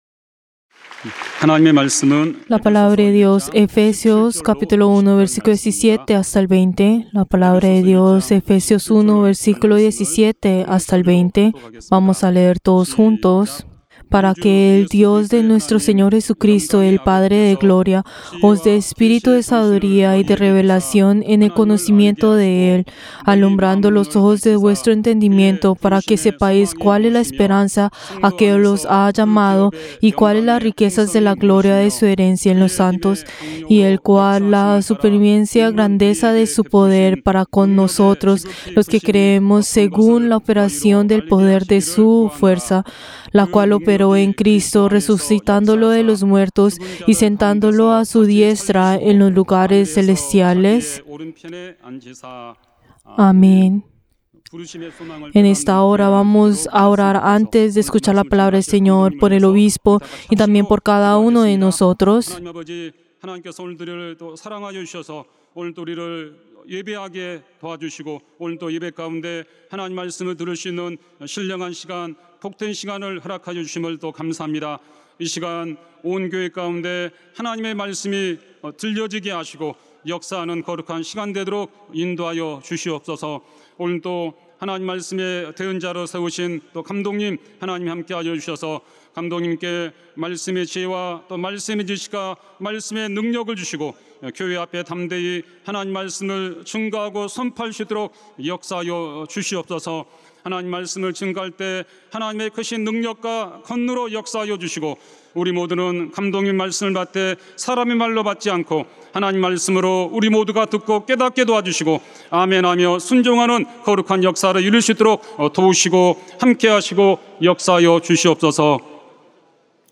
Servicio del Día del Señor del 13 de agosto del 2023